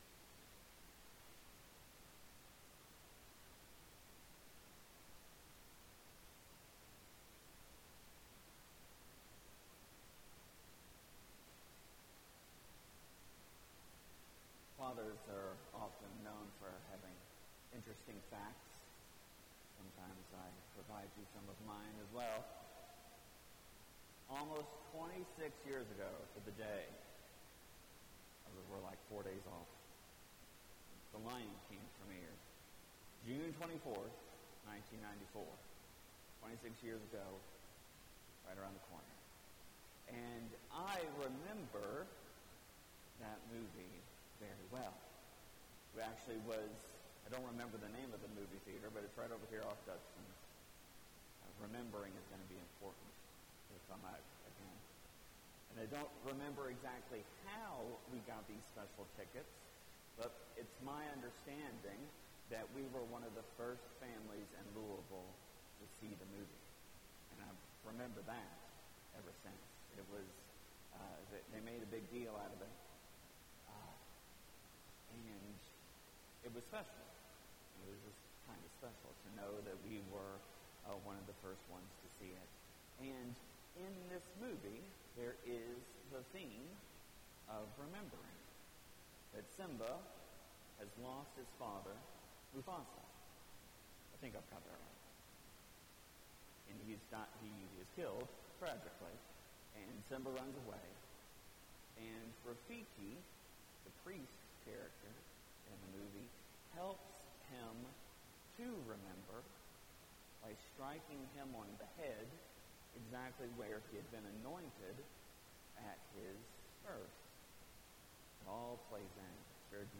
Homily, June 20, 2020 – Ascension Parish and School